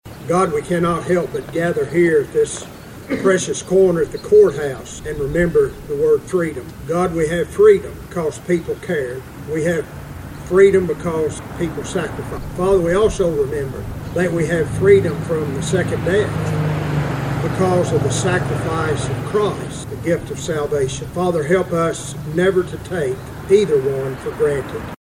The ceremony was held on the front lawn of the Caldwell County Courthouse with a good attendance of residents.